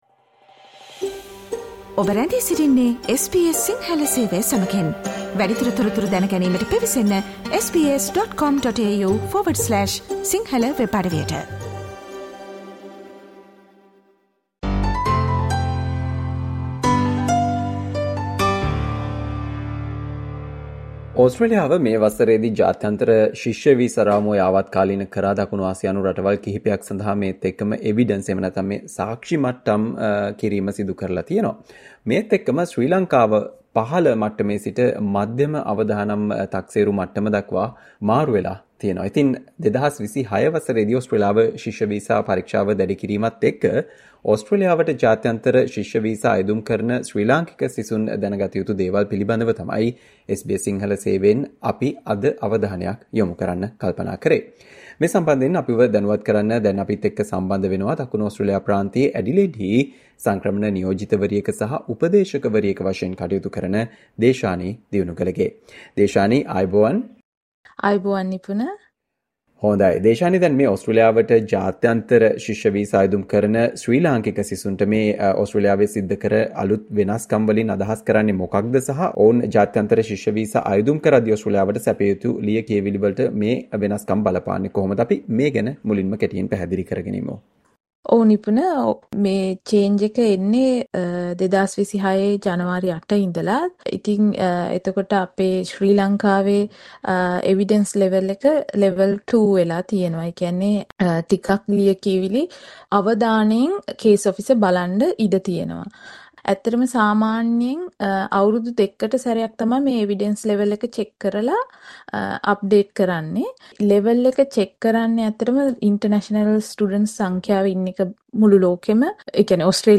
2026 වසරේදී ඕස්ට්‍රේලියාව ශිෂ්‍ය වීසා පරීක්‍ෂාව දැඩි කිරීමත් සමග ඔස්ට්‍රේලියාවට ජාත්‍යන්තර ශිෂ්‍ය වීසා අයදුම් කරන ශ්‍රී ලාංකික සිසුන් දැනගත යුතු දේ පිළිබඳව SBS සිංහල සේවය සිදු කල සාකච්චාවට සවන් දෙන්න